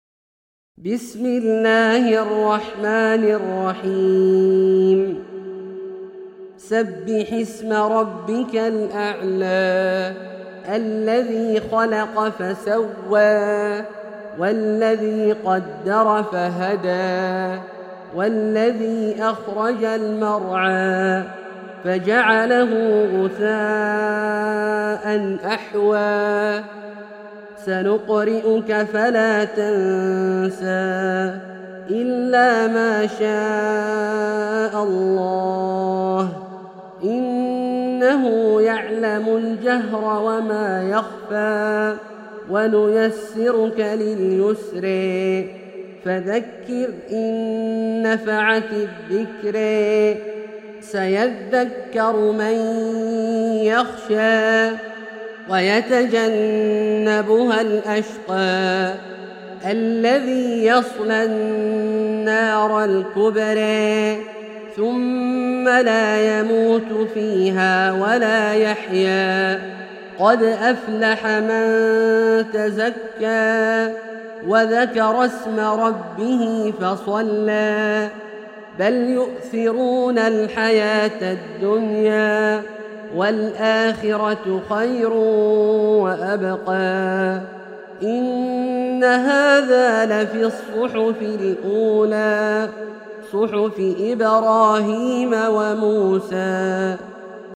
سورة الأعلى - برواية الدوري عن أبي عمرو البصري > مصحف برواية الدوري عن أبي عمرو البصري > المصحف - تلاوات عبدالله الجهني